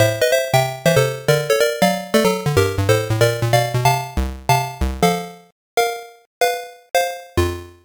The jingle
Source Recorded from the Sharp X1 version.